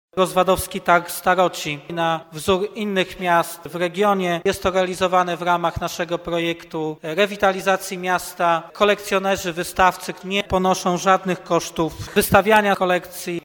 Do Rozwadowa powraca targ staroci. Po długiej przerwie impreza powraca na rozwadowski Rynek. Jest realizowana w ramach projektu rewitalizacji Stalowej Woli, o czym informował prezydent Stalowej Woli Lucjusz Nadbereżny: